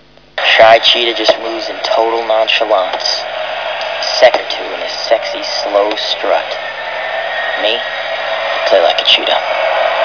Here are some wav sounds taken from the film